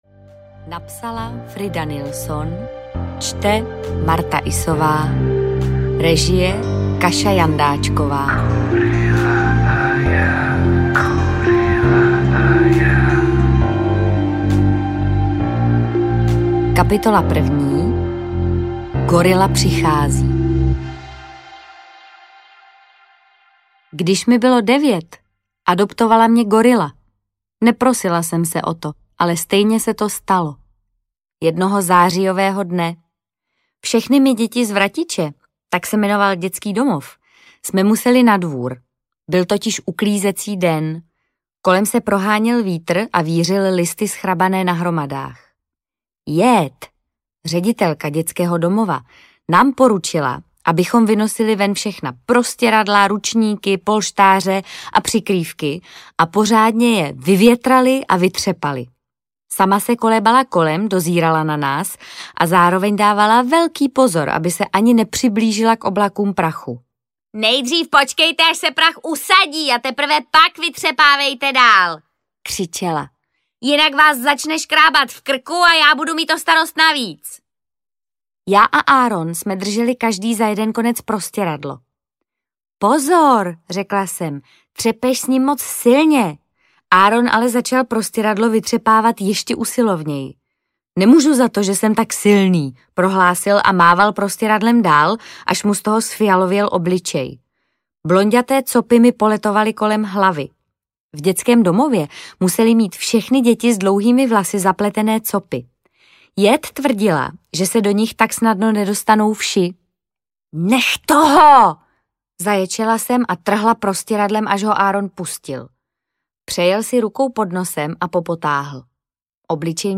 Gorila a já audiokniha
Ukázka z knihy
• InterpretMartha Issová